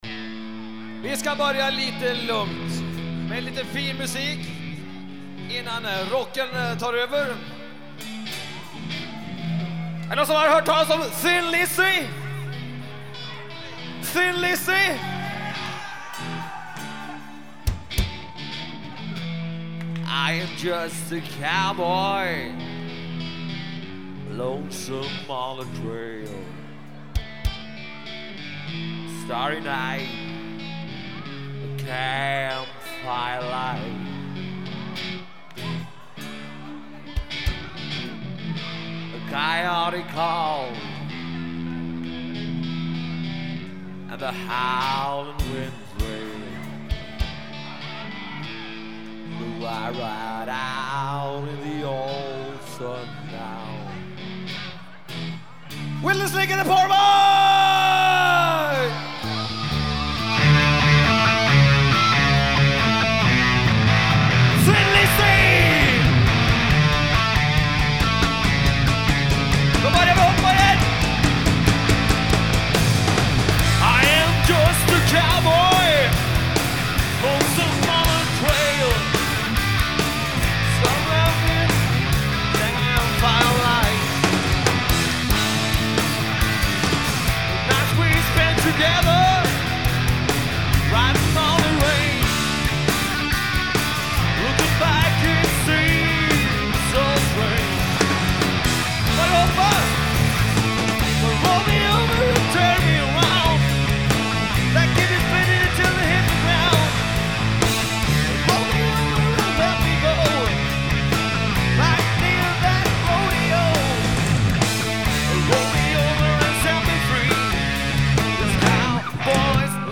Konceptet var enkelt; akustisk gitarr, bas och sång.